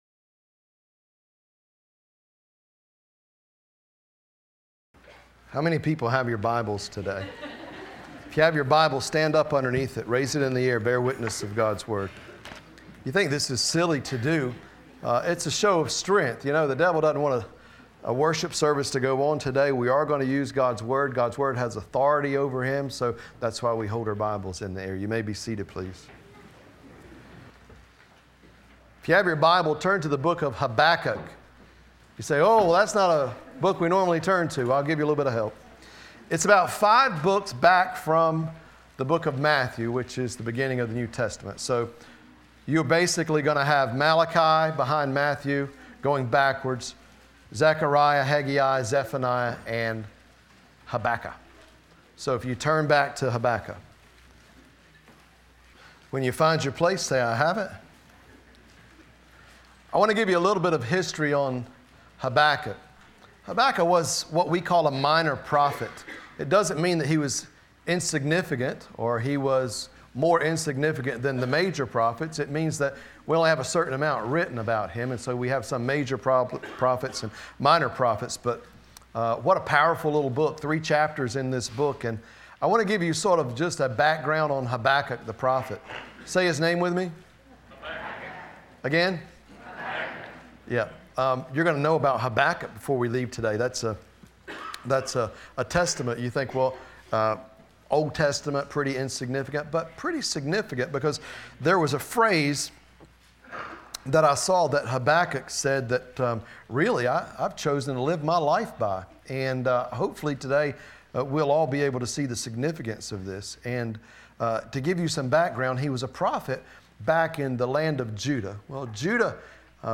Listen to Sermons - Nazareth Community Church